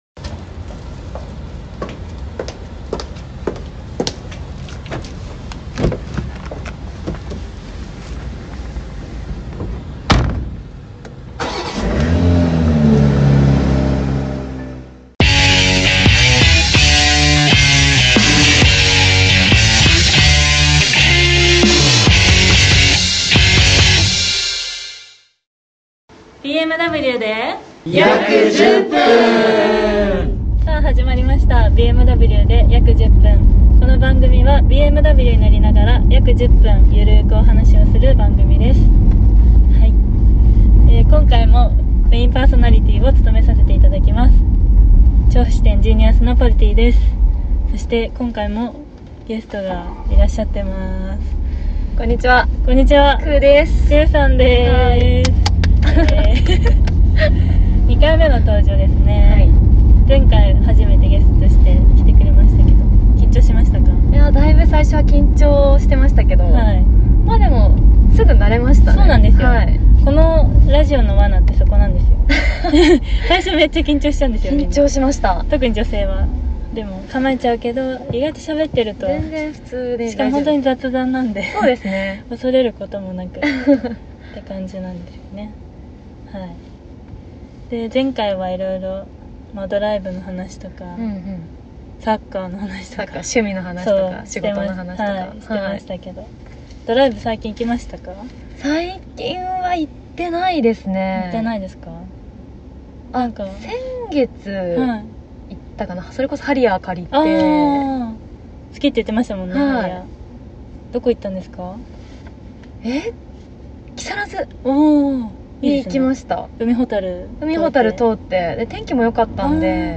ＢＭＷで約１０分 ＃１４ 女子トーク ドライブってお腹すくよね。